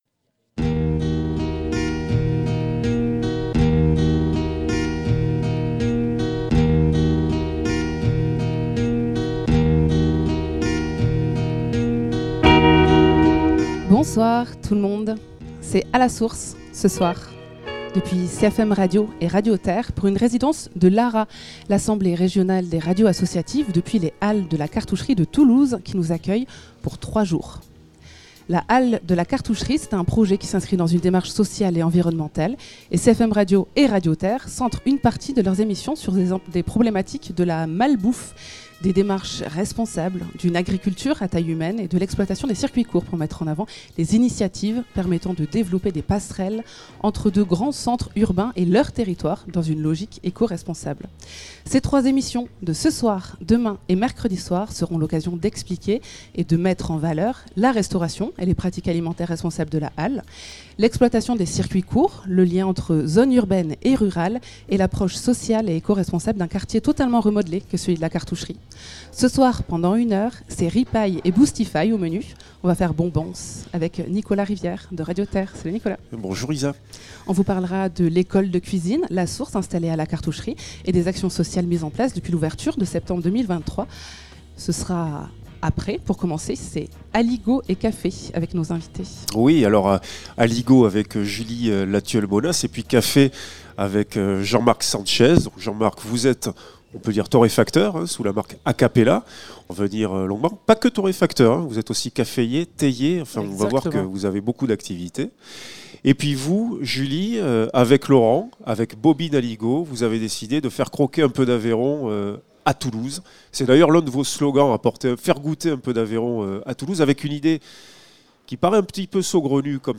De l’aligot et du café : rencontre avec les restaurateurs présents dans les halles de Cartoucherie.